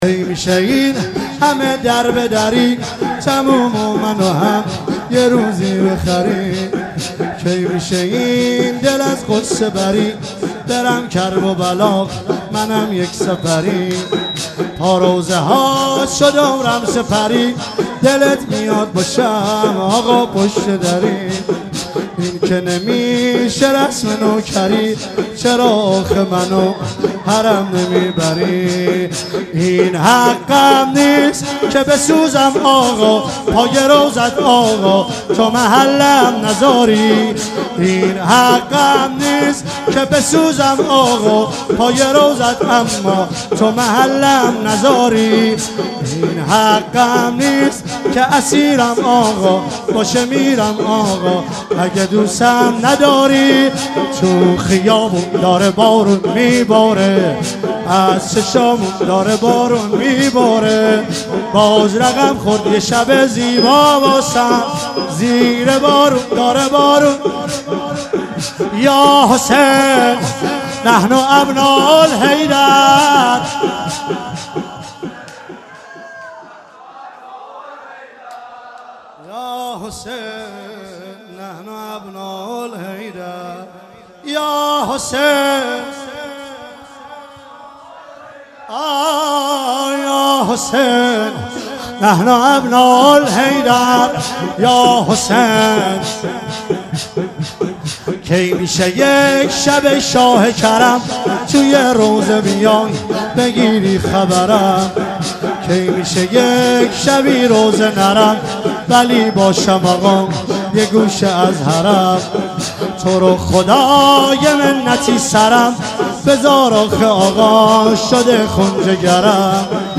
مراسم وفات حضرت معصومه ۱۳۹۶
هیئت حضرت زینب سلام الله علیها – نیشابور